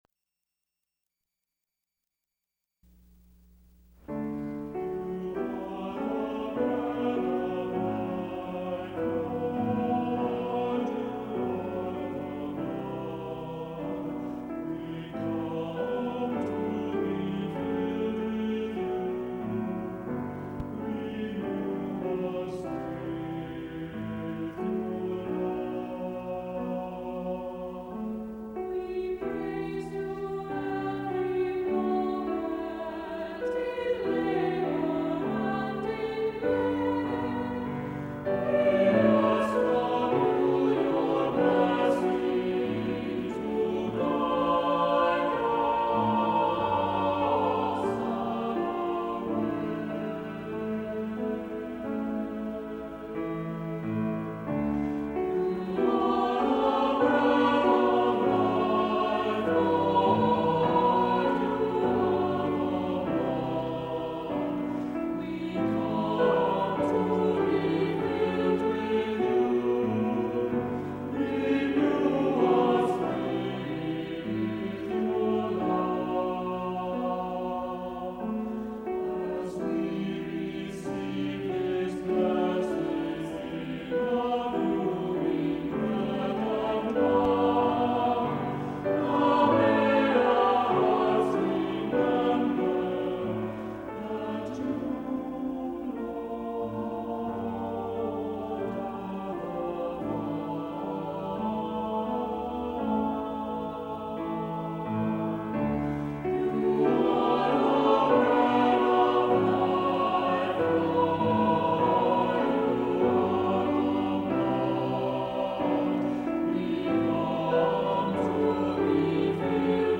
SATB, keyboard